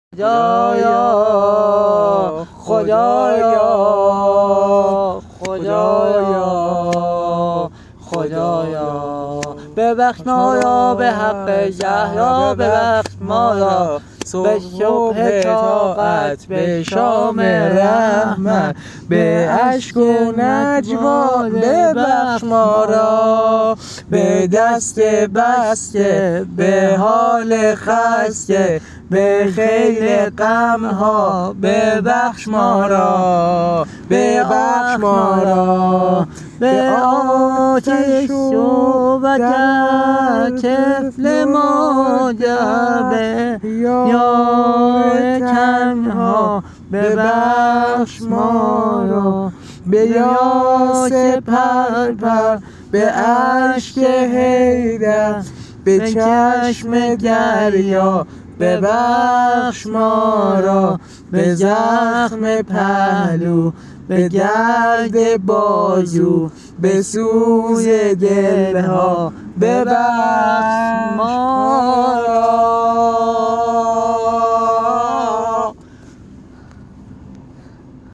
مداح اهل بیت